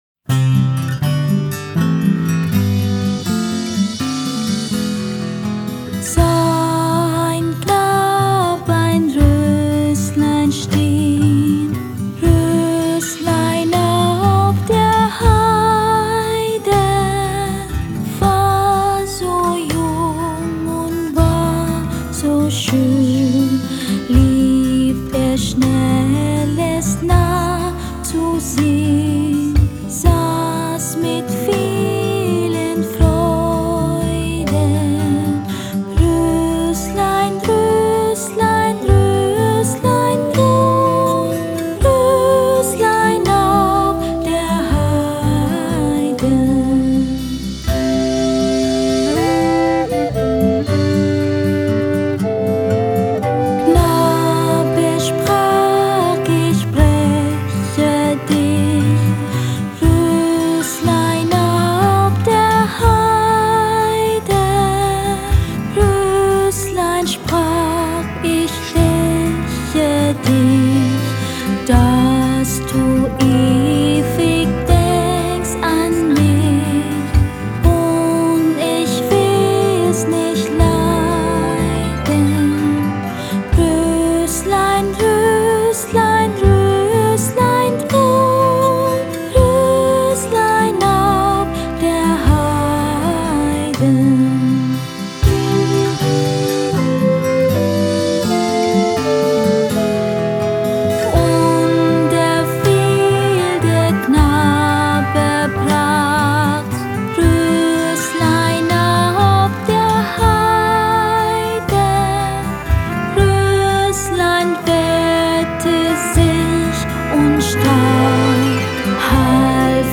Traditionelle Lieder